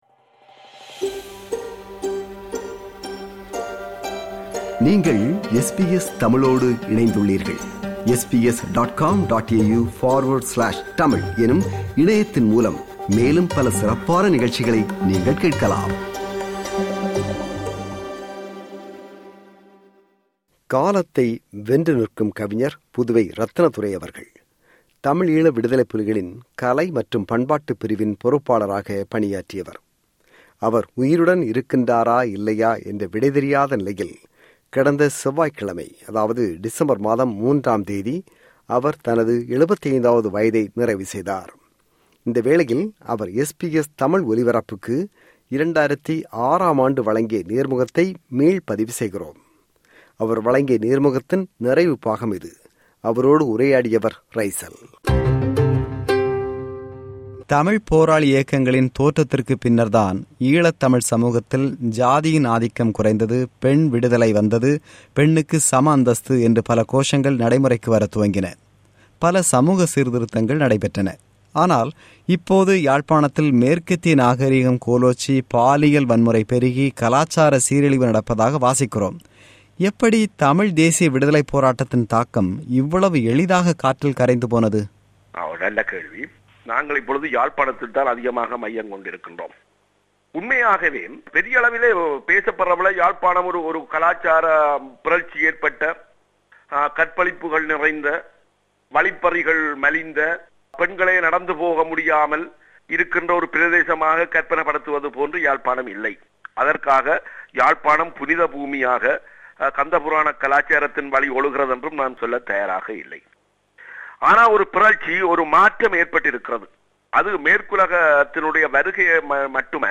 இந்த வேளையில் அவர் SBS தமிழ் ஒலிபரப்புக்கு 2006 ஆம் ஆண்டு வழங்கிய நேர்முகத்தை மீள் பதிவு செய்கிறோம்.
நேர்முகம் – பாகம் 2.